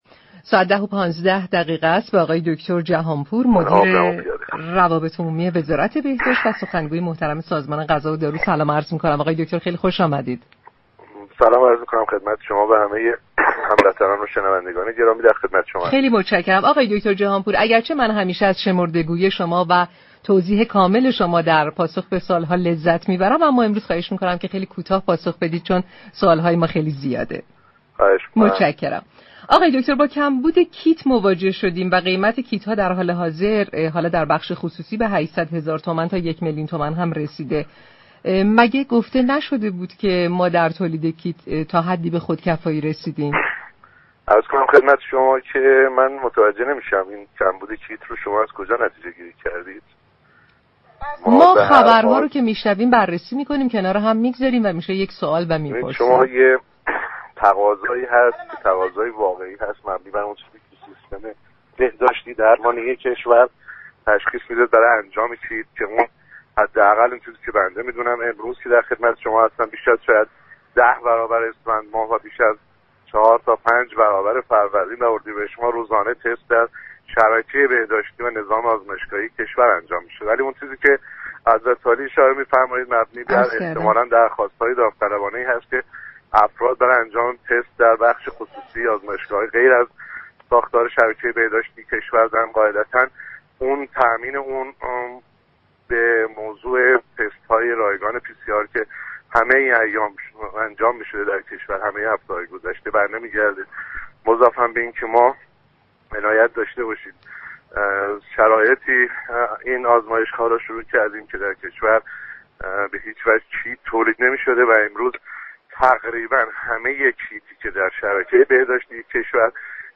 در گفتگو با تهران كلینیك رادیو تهران